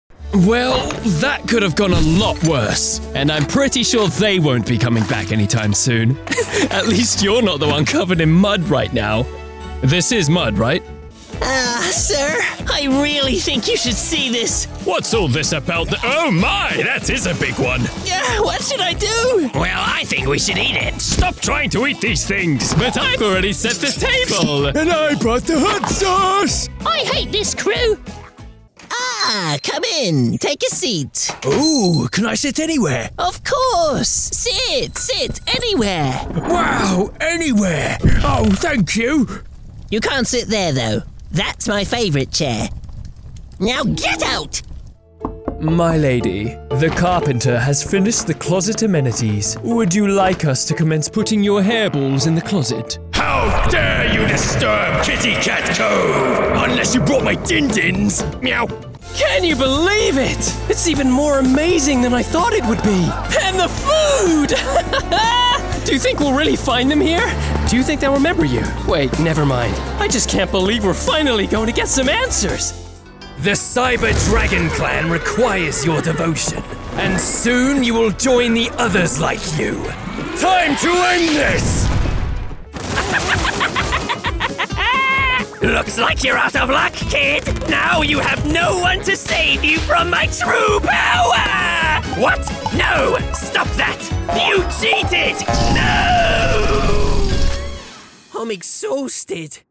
Animation
– Recorded in a sound-treated booth
I specialise in performing Voice overs in both American and British accents and dialects.